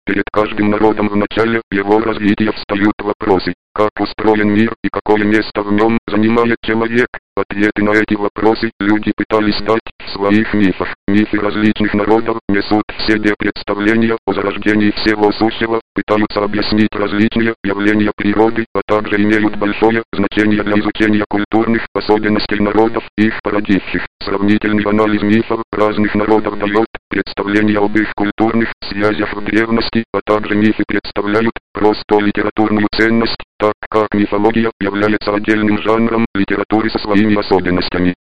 Скачать демонстрационный аудиофайл дополнительного голоса Newfon [226 kB]
tctts-newfon.mp3